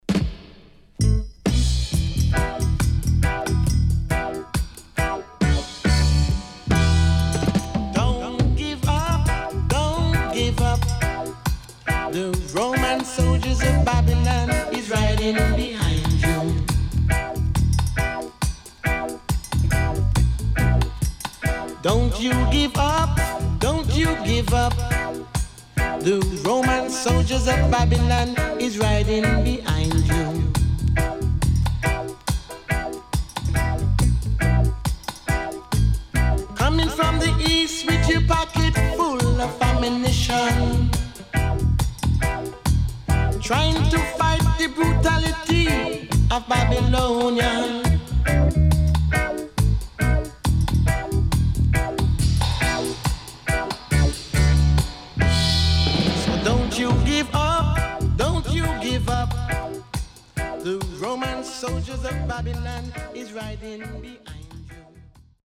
【12inch】